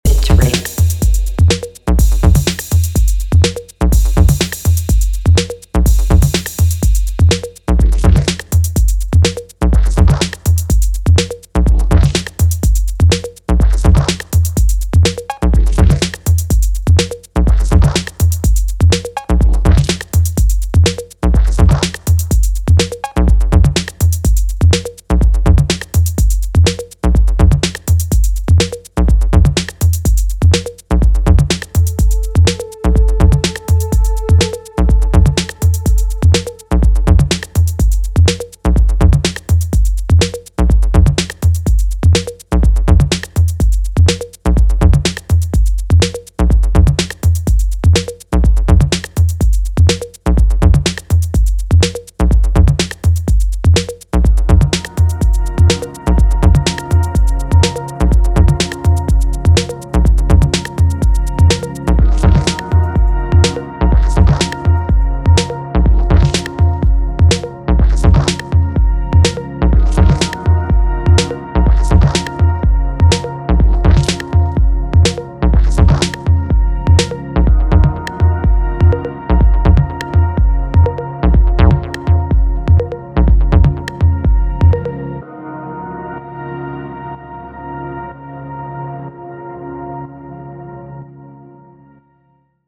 massive balearic summer anthem